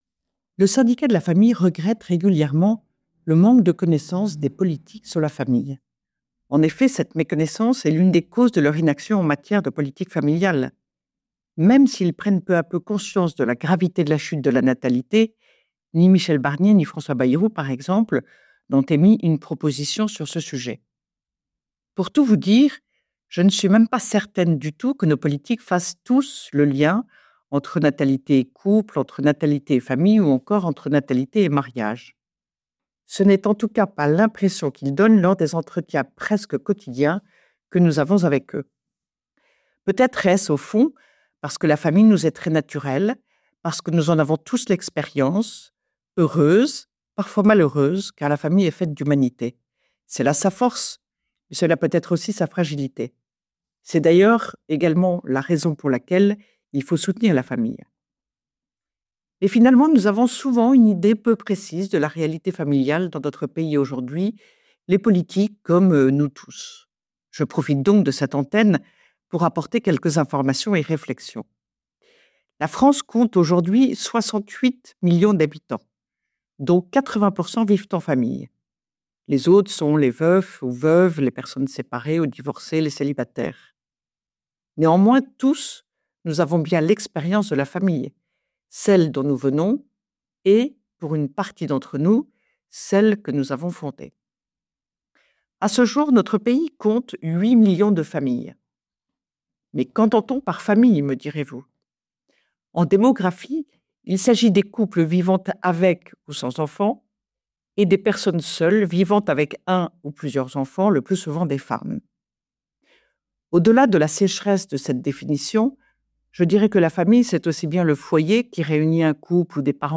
« Esprit de Famille » : Retrouvez chaque semaine la chronique de Ludovine de La Rochère, diffusée le samedi sur Radio Espérance, pour connaître et comprendre, en 3 minutes, l’essentiel de l’actualité qui concerne la famille.